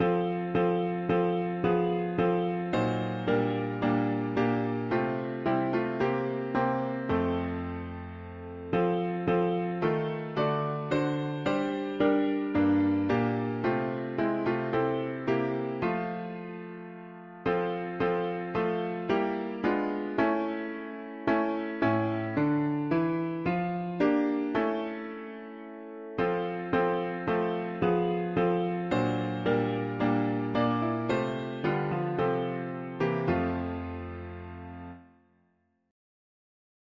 Yet in thy dark streets shineth the … english christian 4part winter evening
Soprano sings the highest, most important notes (the melody).
Key: F major